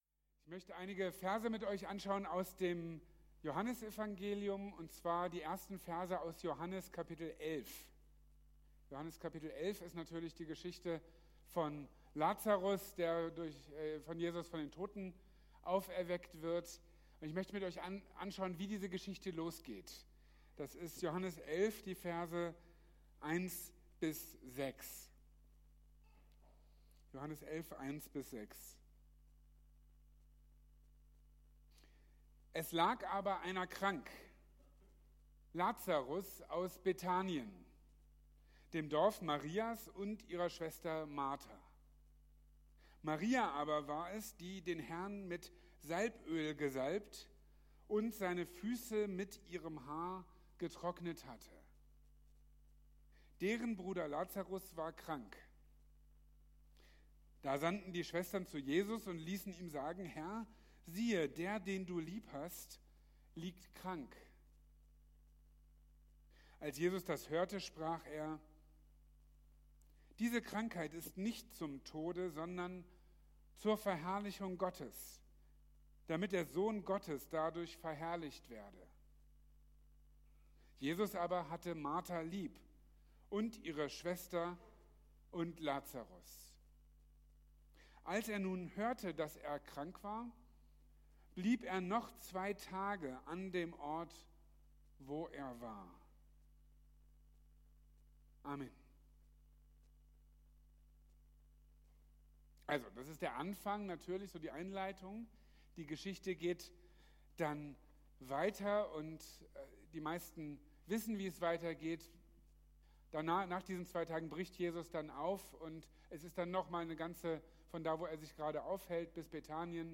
Liebe und Herrlichkeit | Marburger Predigten